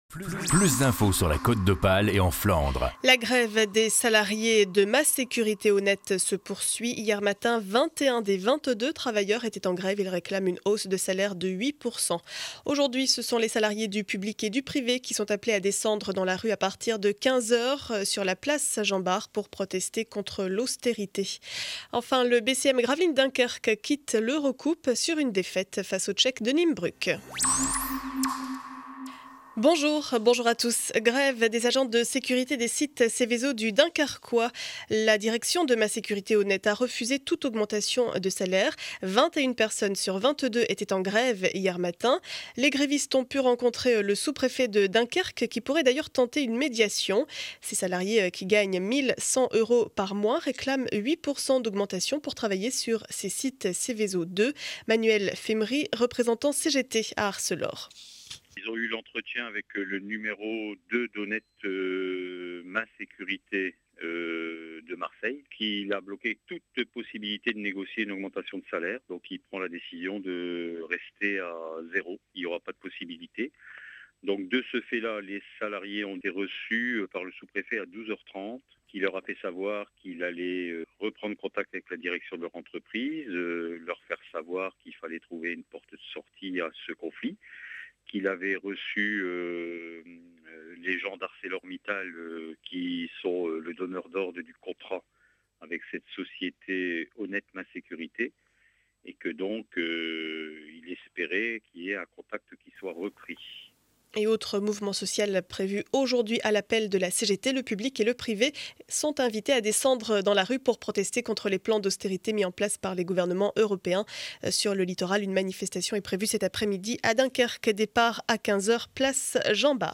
Journal du mercredi 29 février 2012 7 heures 30 édition du Dunkerquois.